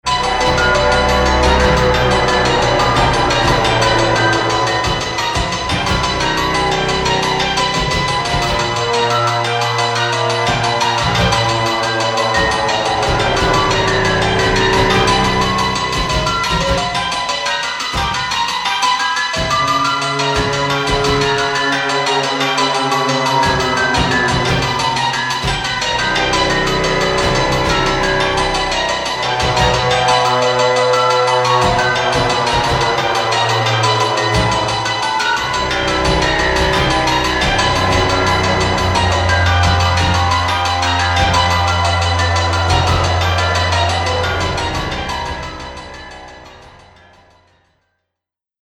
Original Synth Version